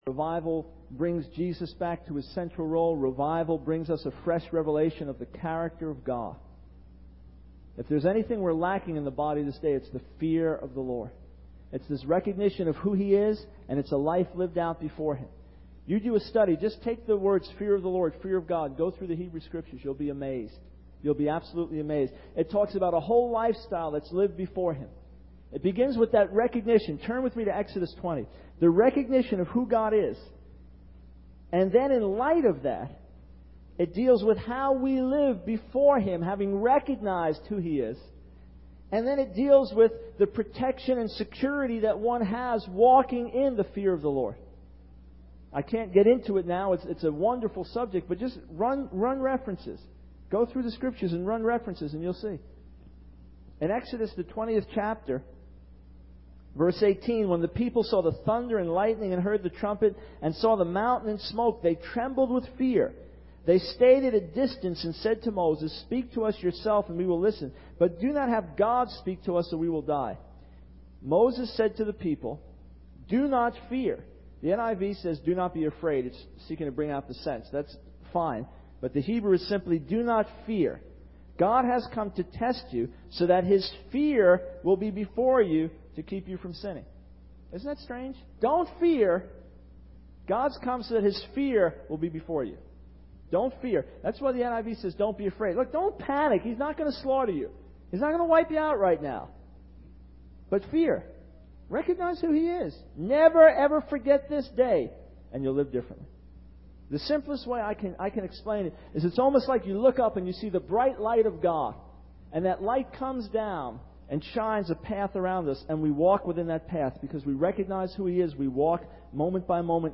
In this sermon, the speaker highlights the current state of believers in the United States, noting that there are more believers now than ever before. However, despite the abundance of churches, ministries, and Christian media, society is crumbling and the fear of God is disappearing. The speaker emphasizes the importance of believers being the salt of the earth and the light of the world, living in a way that glorifies God and convicts others of their sin.